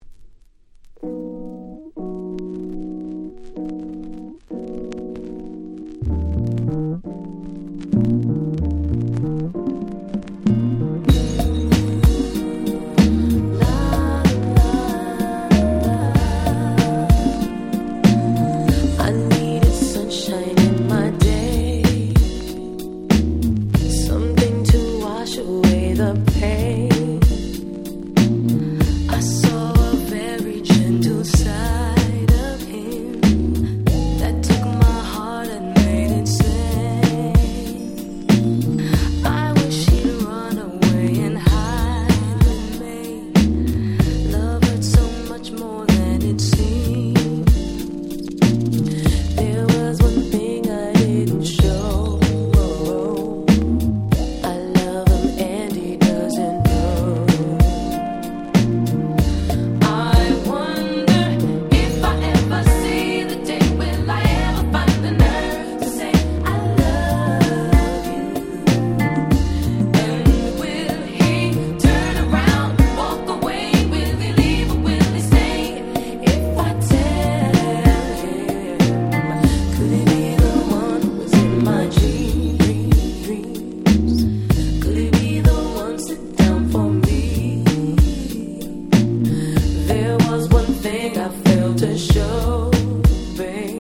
【Media】Vinyl 12'' Single
97' R&B Classic !!